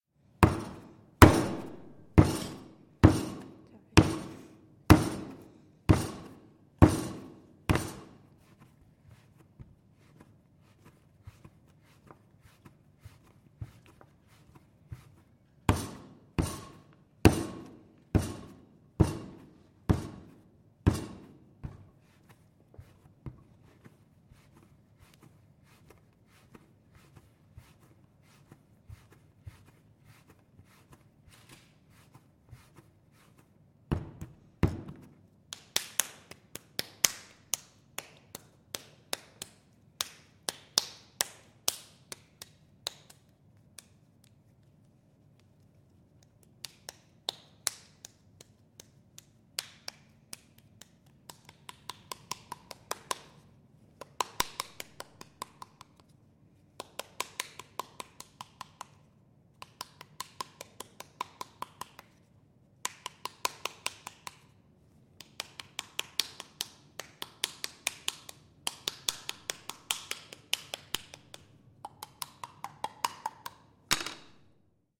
Creating a pinch pot using clay, hands, table, and wooden spatula